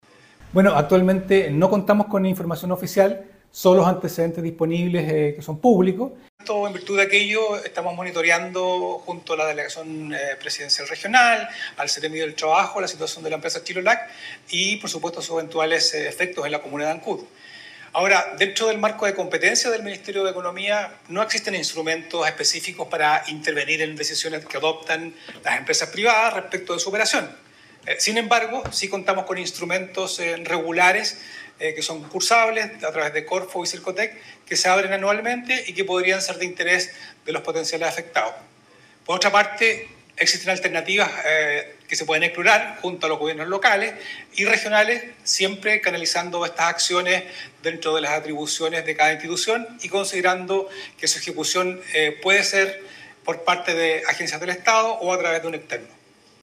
Sobre lo sucedido, que deja prácticamente todo el personal sin su fuente laboral desde la seremía de Economía de la región de Los Lagos se informó que se trata de una empresa privada por lo que las vías de apoyo o financiamiento son bastante acotadas, indicó el seremi Luis Cárdenas.